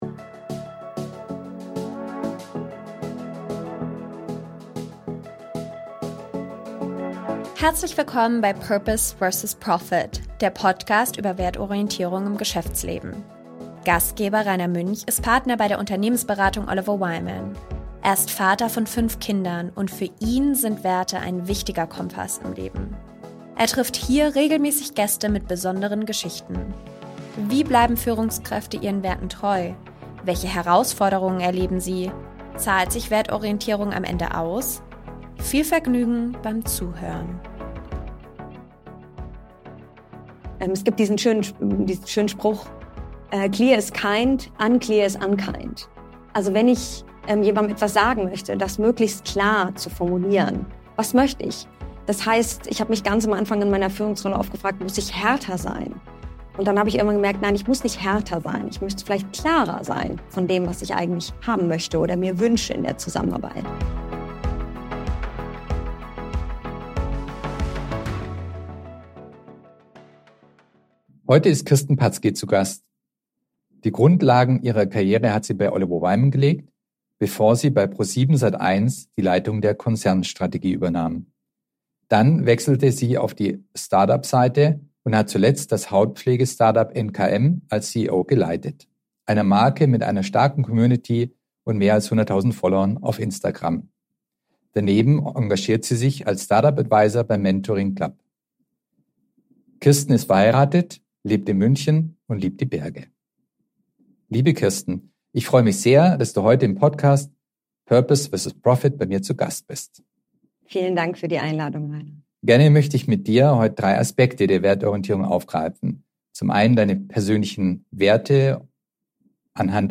Ein Podcast-Gespräch über Führung, berufliche Weiterentwicklung, persönliches Wachstum sowie gelegentliches Innehalten, damit die Batterie am Ende des Tages nicht immer leer ist.